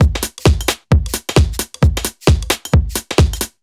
Index of /musicradar/uk-garage-samples/132bpm Lines n Loops/Beats
GA_BeatD132-02.wav